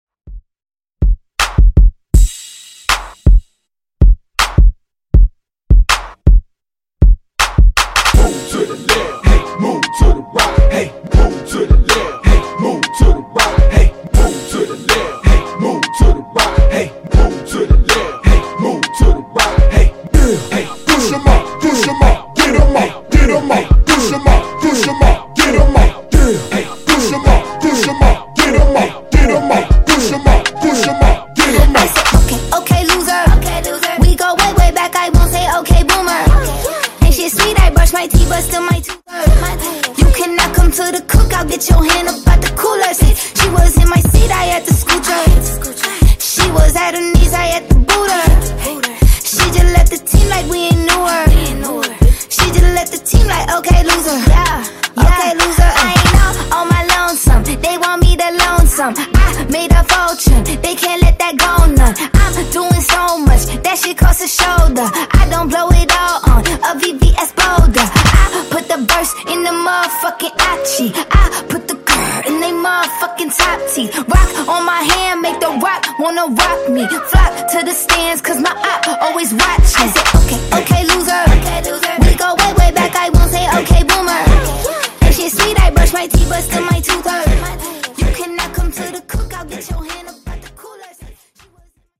Genres: HIPHOP , RE-DRUM , TOP40 Version: Clean BPM: 80 Time